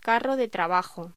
Locución: Carro de trabajo
voz